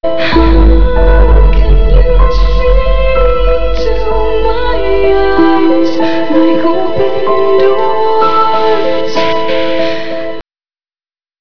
una extraordinaria balada de piano.